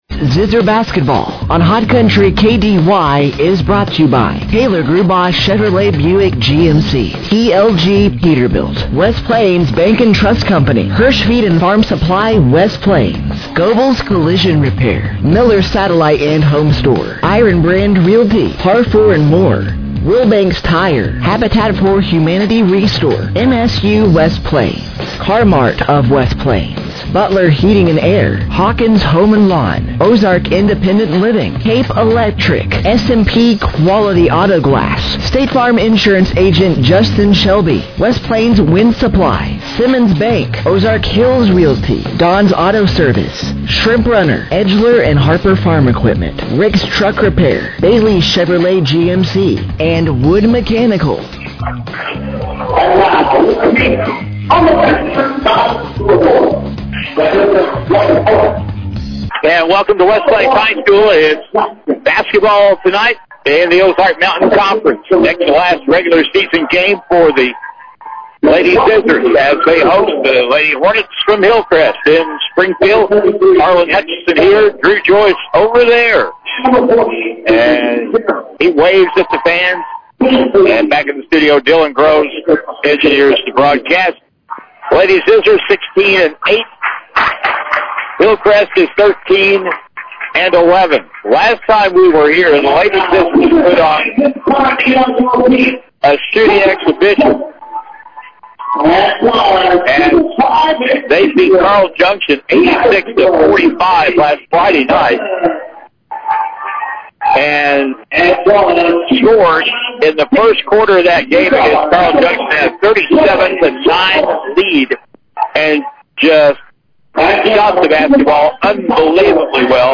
Game Audio Below:
The West Plains Lady Zizzers played their next to last home game this past Monday night, February 23rd, 2026 from West Plains High School as they took on The Hillcrest Lady Hornets.